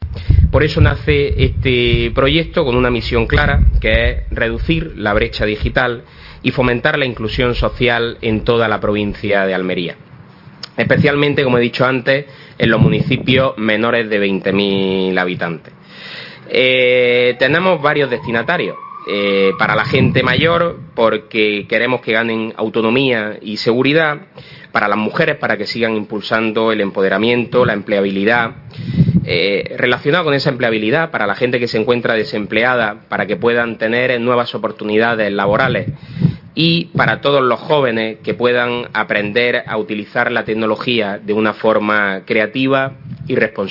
El aparcamiento del Pabellón Moisés Ruiz ha albergado la presentación del Programa de Capacitación Digital que arranca esta semana hasta el mes de junio fruto del acuerdo de colaboración entre la Diputación Provincial y la Junta de Andalucía.